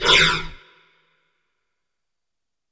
Heavy Breaths
BREATH3W-R.wav